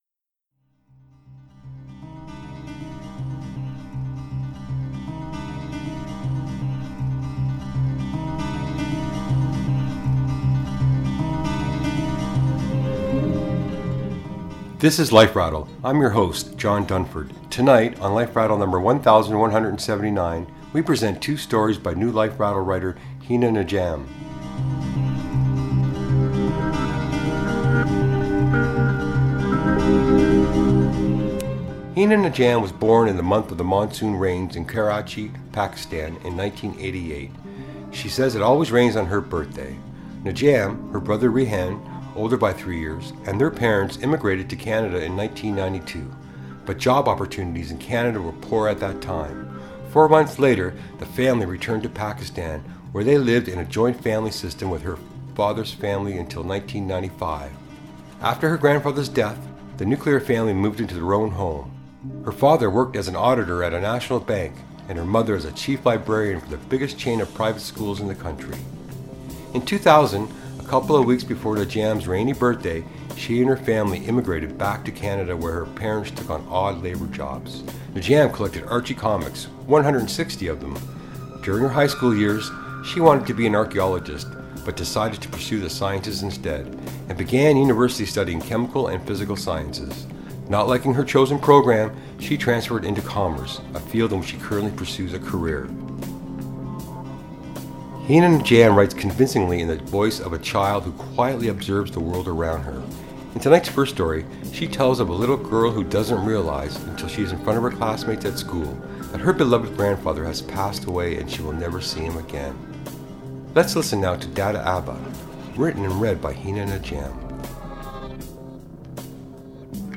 reading her stories "Dada Abba" and "The Sautans"